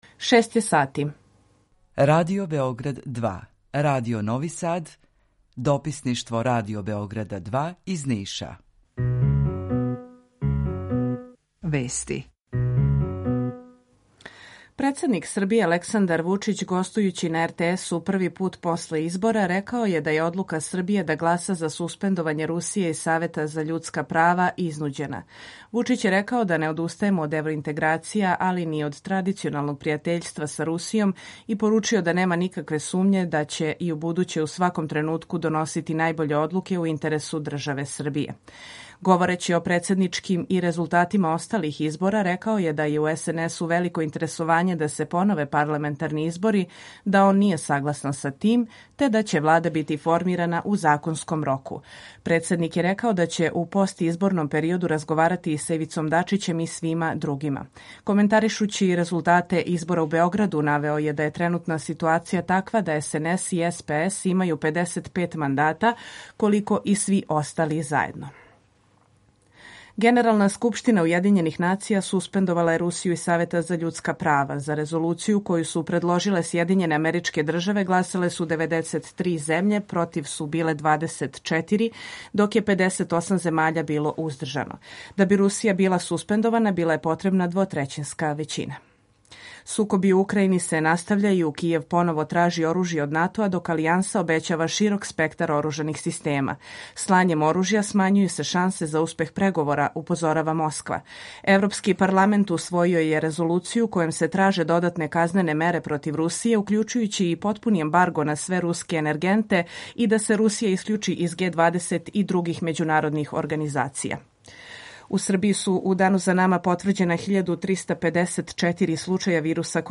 Емисију реализујемо заједно са студиом Радија Републике Српске у Бањалуци и са Радио Новим Садом.
У два сата, ту је и добра музика, другачија у односу на остале радио-станице.